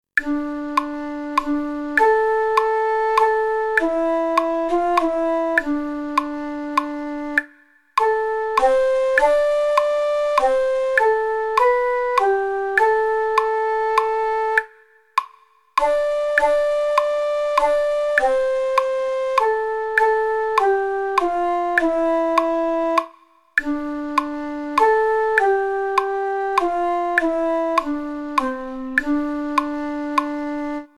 Bella melodía tradicional inglesa
Arreglo para flauta dulce en pdf y mp3.